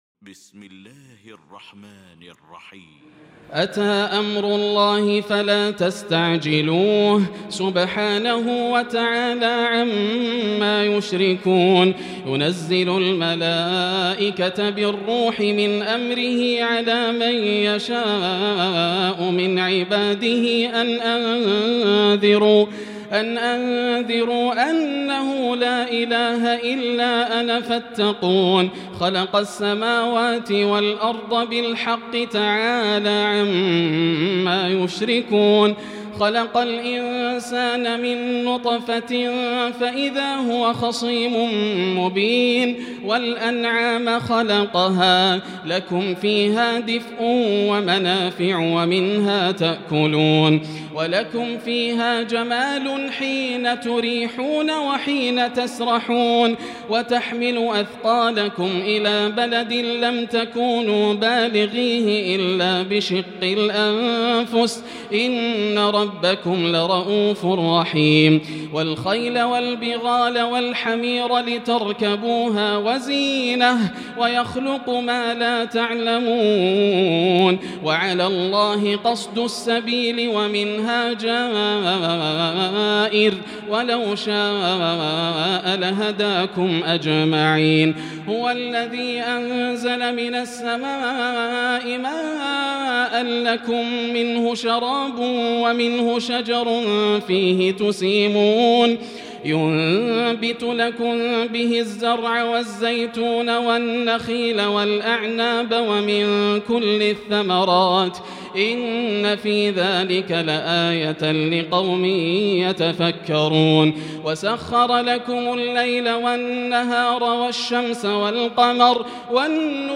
المكان: المسجد الحرام الشيخ: سعود الشريم سعود الشريم معالي الشيخ أ.د. عبدالرحمن بن عبدالعزيز السديس فضيلة الشيخ ياسر الدوسري النحل